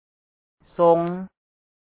The second so2N (the "o" of so2N is pronounced like English "oh"), however, is written
ຊົງ  using the ta#m [Low Class] "s" since a ta#m class initial consonant followed by a short or long vowel plus a sonorant final is pronounced with a high tone.